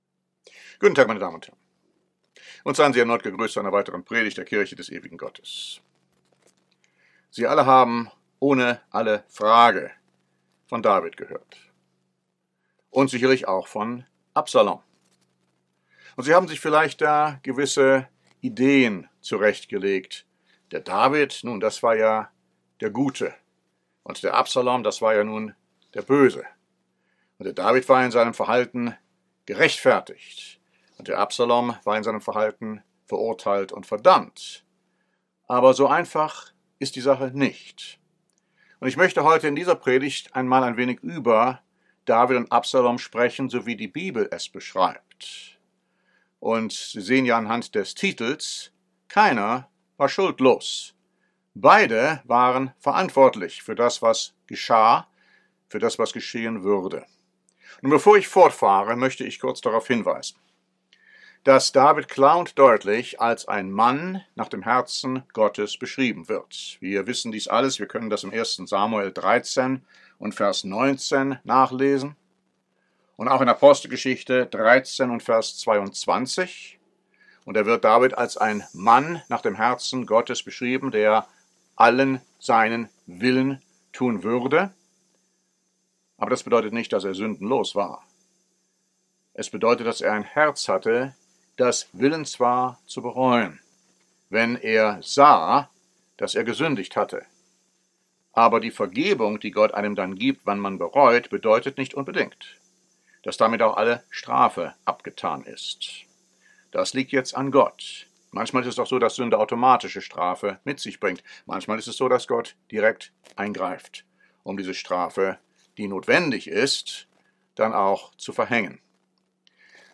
Diese Predigt zeigt, dass Sünde Konsequenzen hat, und Gottes Vergebung nicht unbedingt Straflosigkeit bedeutet.